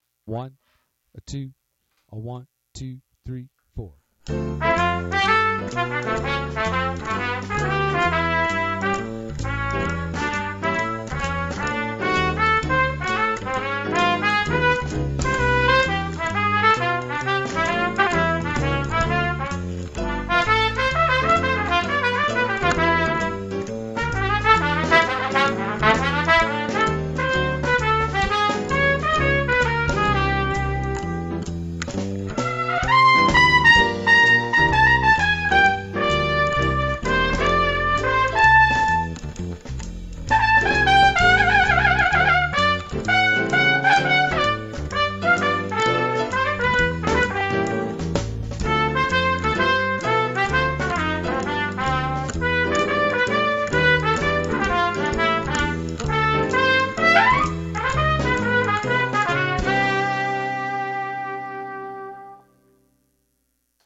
Trumpet
Swing Etude
Performance with Accompaniment
set-1-swing-with-bkgrd-v21.m4a